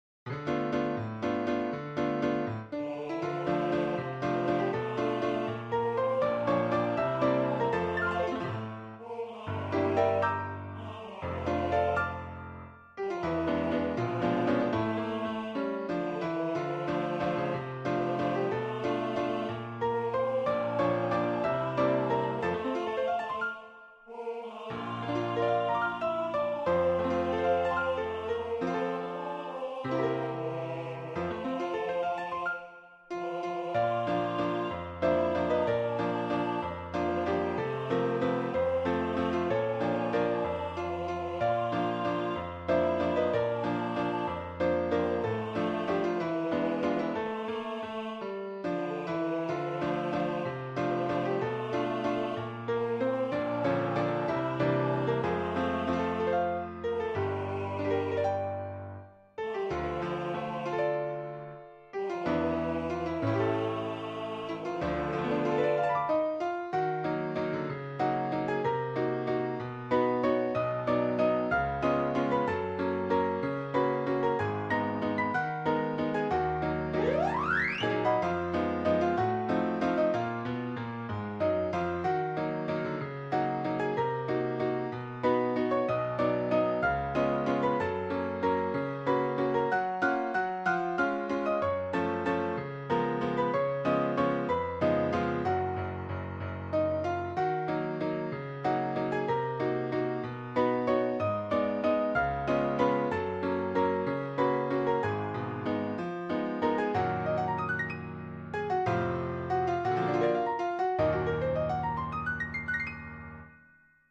Ноты для фортепиано.